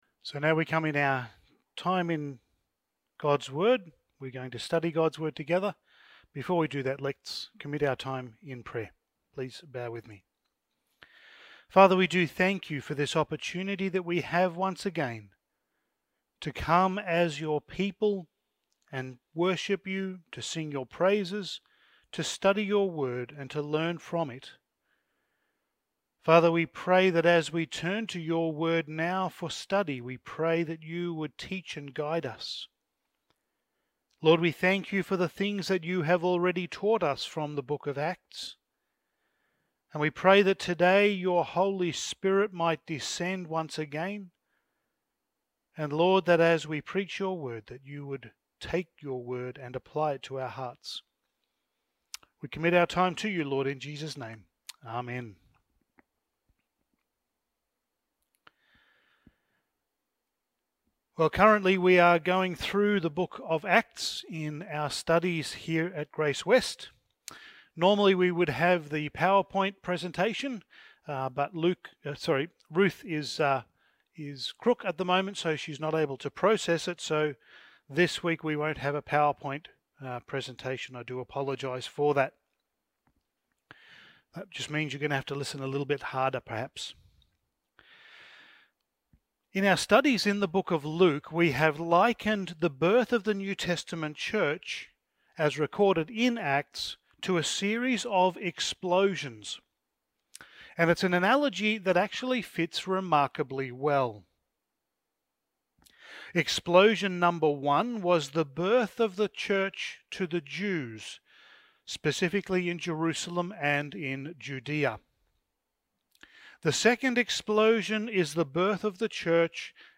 Passage: Acts 8:26-40 Service Type: Sunday Morning